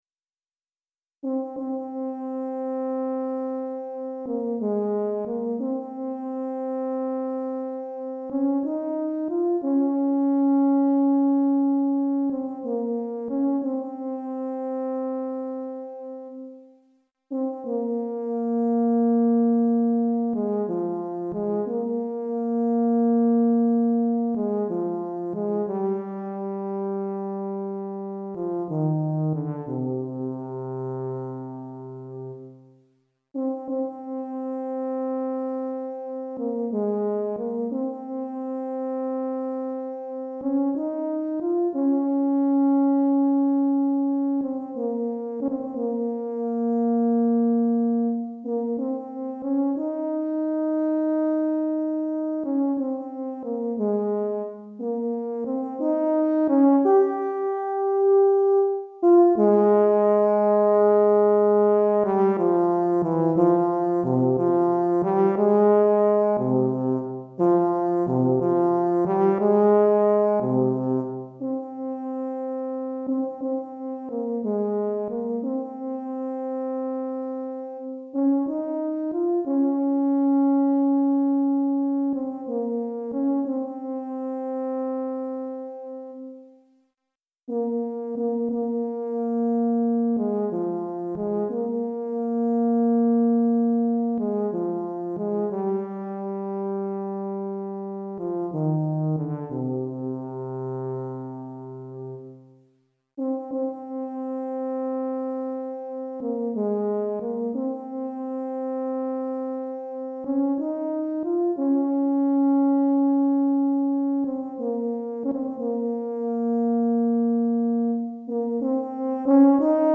Tuba Solo